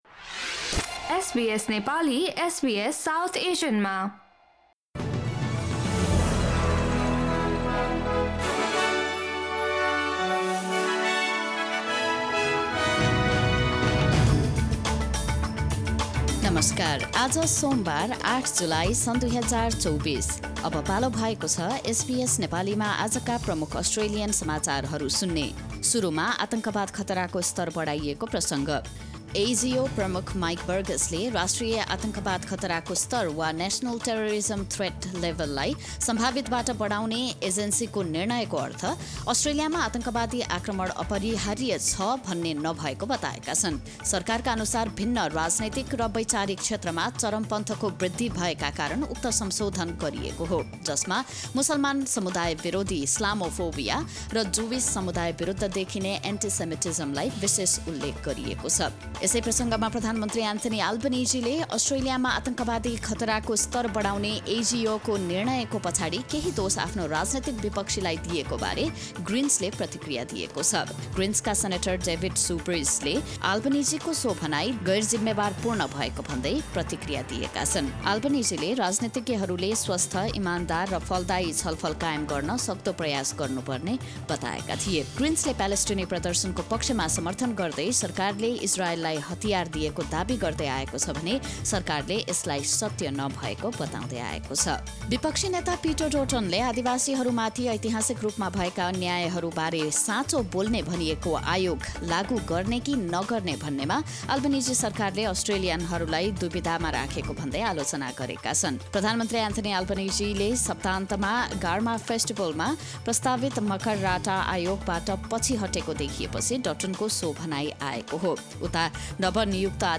SBS Nepali Australian News Headlines: Monday, 5 August 2024